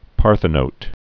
(pärthə-nōt)